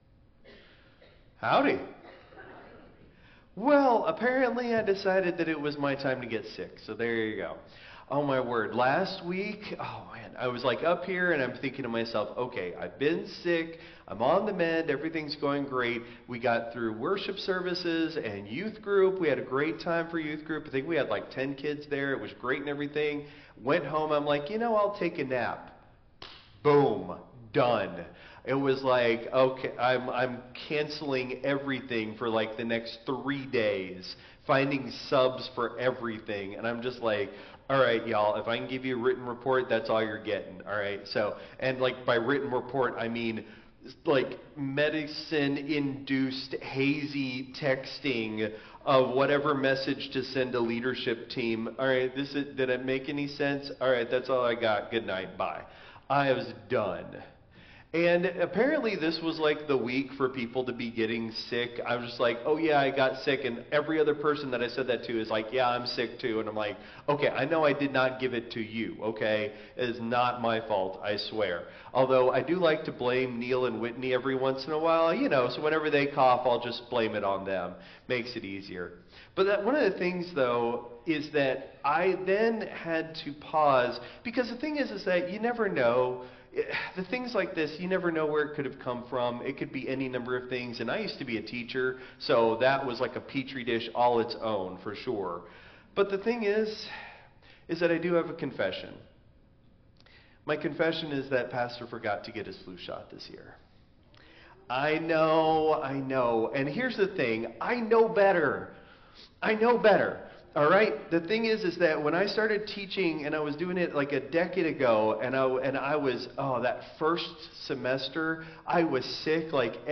Christ Memorial Lutheran Church - Houston TX - CMLC 2025-01-12 Sermon (Traditional)